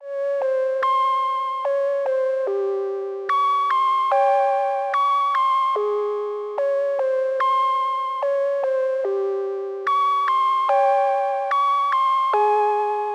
Feral_Bell.wav